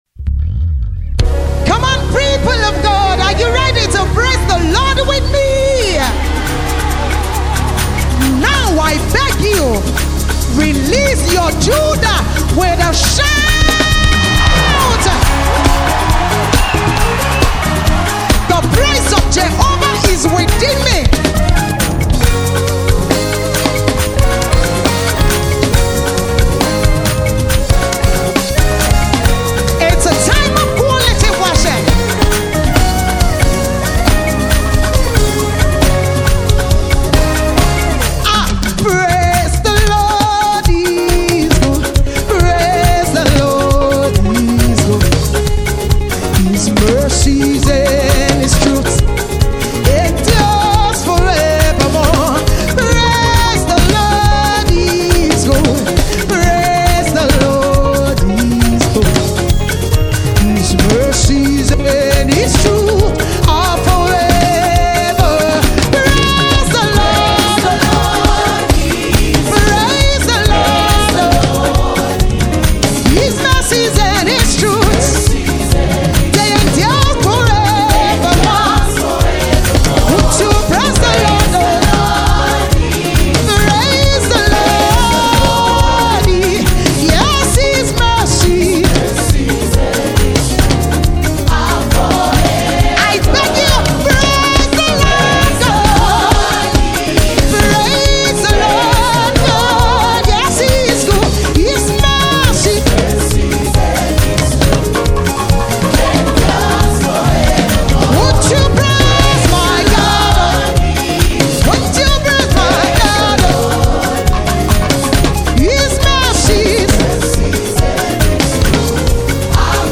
powerful worship song